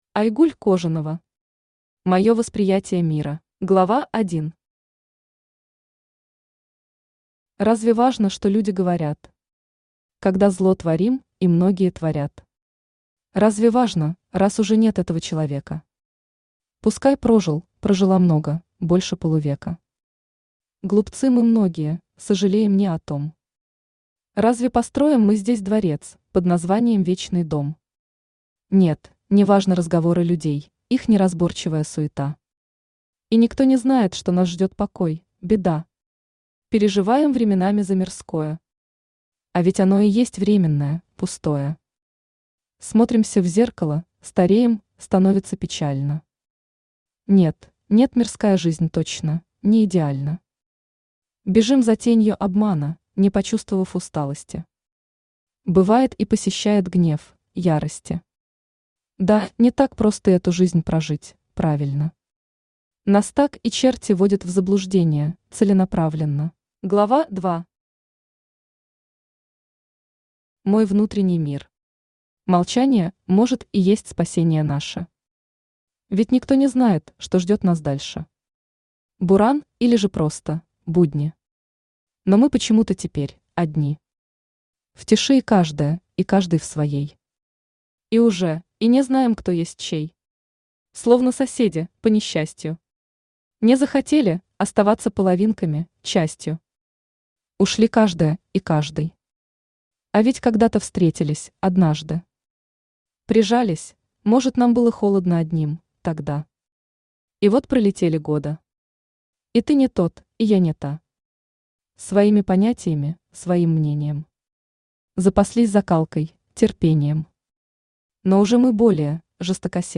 Аудиокнига Моё восприятие мира | Библиотека аудиокниг
Aудиокнига Моё восприятие мира Автор Айгуль Табылдовна Кожанова Читает аудиокнигу Авточтец ЛитРес.